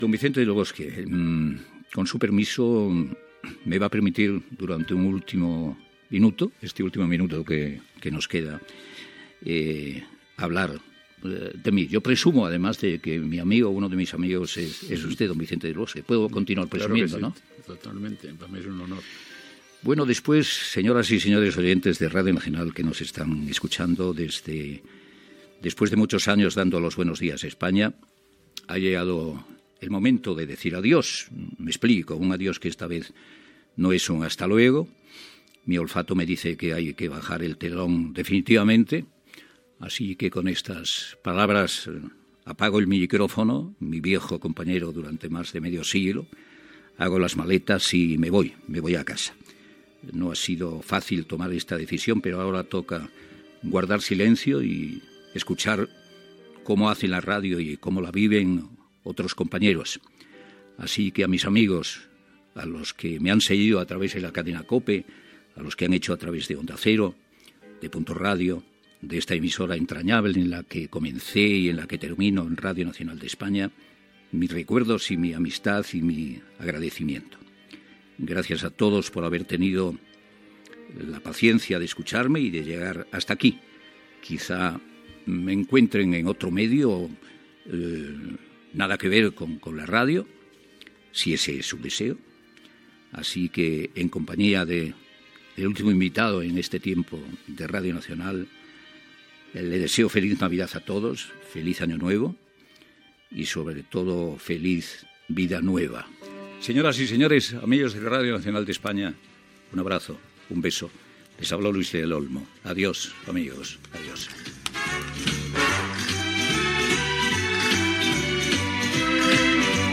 Després d'una entrevista a l'entrenador de futbol Vicente del Bosque, Luis del Olmo s'acomiada de la ràdio per sempre.
Info-entreteniment
Presentador/a
Olmo, Luis del